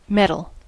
Ääntäminen
Ääntäminen US : IPA : [ˈmɛ.tᵊl] UK : IPA : [ˈmɛ.tᵊl] Tuntematon aksentti: IPA : /ˈmɛtəl/ IPA : [ˈmɛ.ɾl̩] Haettu sana löytyi näillä lähdekielillä: englanti Käännös Konteksti Substantiivit 1.